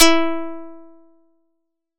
This program creates a plucked string using wavetable synthesis, based on the approach
String #2 Percent: 5
Sympathetic mix : 50
string is the highest string on the guitar (E). There user should be able to select